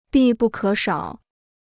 必不可少 (bì bù kě shǎo): absolutely necessary; indispensable.